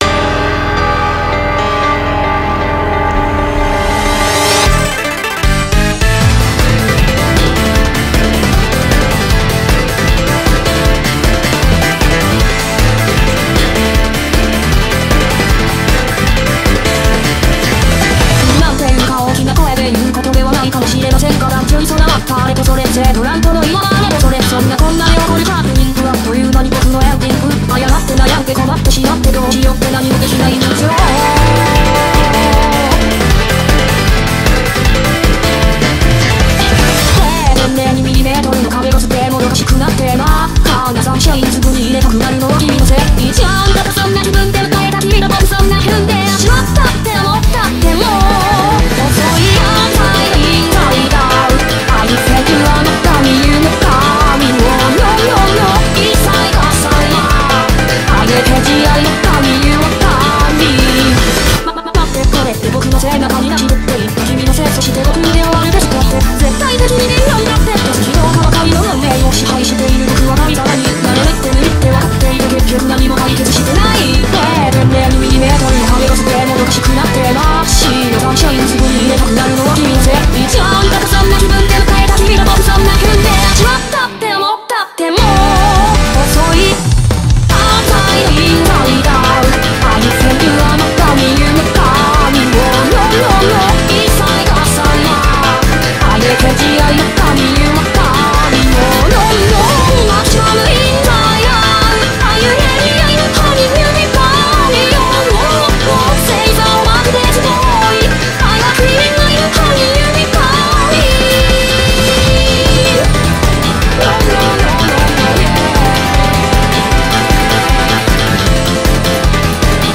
BPM155
MP3 QualityMusic Cut